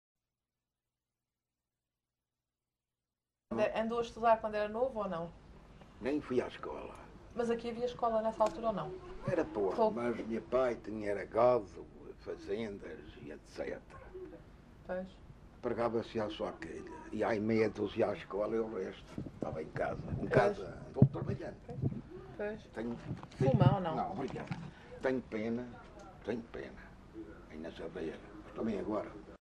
LocalidadeTanque (Porto Santo, Funchal)